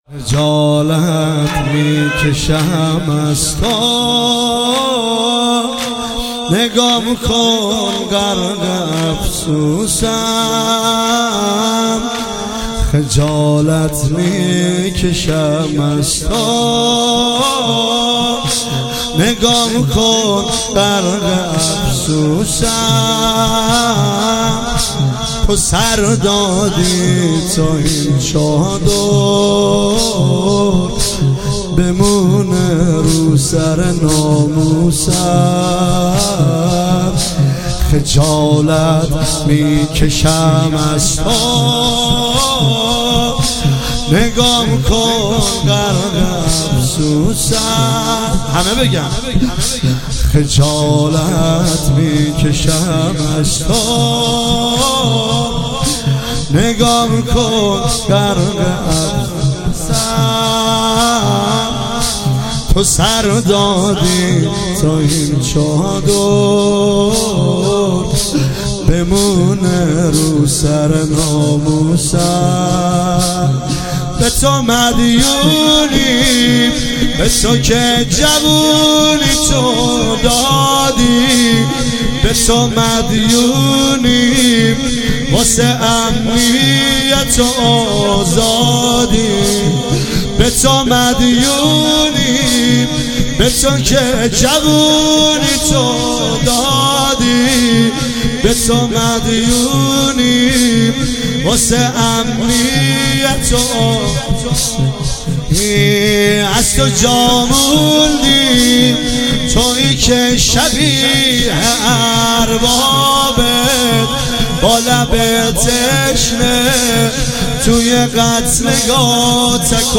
هیئت بین الحرمین کردکوی -زمینه - خجالت میکشم از تو